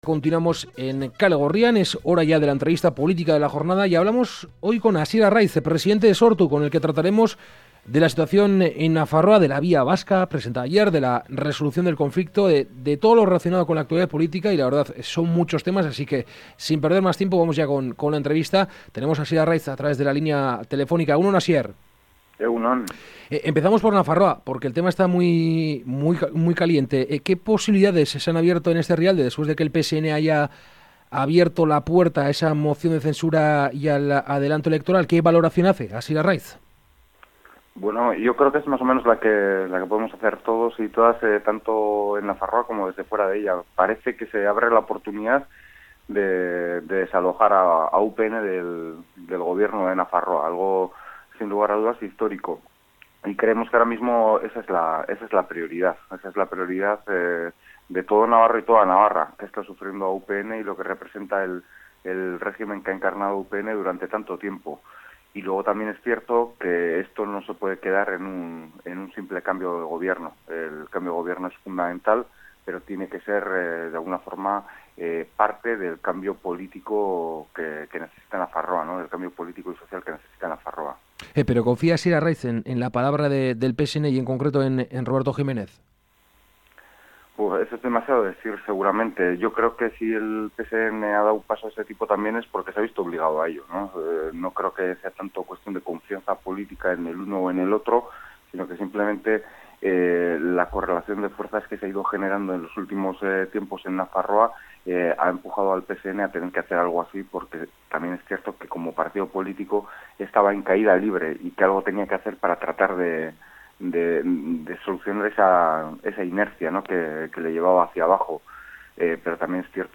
Esta mañana en Kalegorrian hemos entrevistado a Hasier Arriaz, presidente de Sortu, que ha hablado, entre otras cuestiones, sobre el nuevo ciclo político que parece abrirse en Nafarroa.